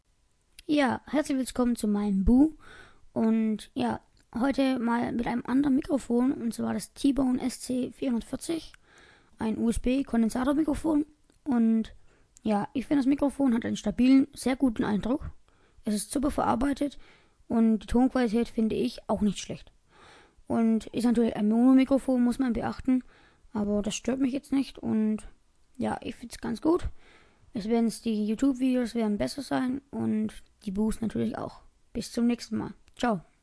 USB Kondensator Mikrofon Test